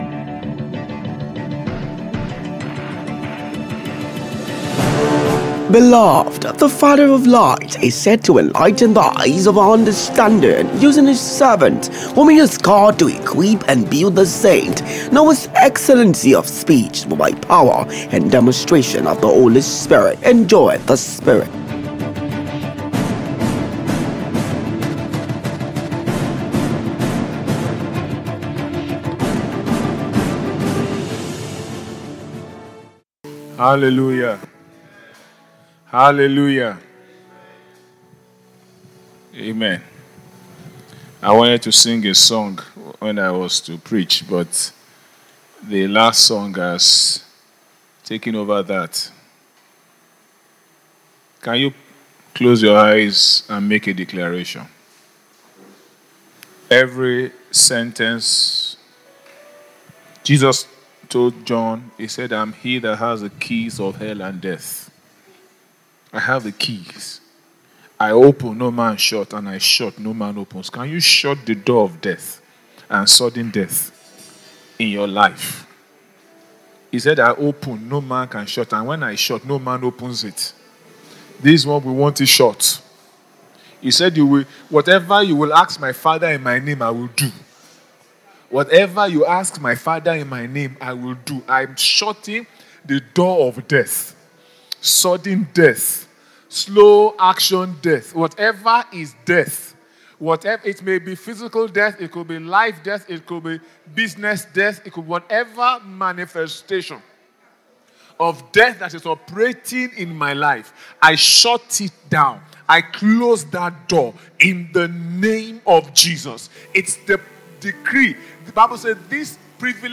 August-Communion-Service-Exhortation.mp3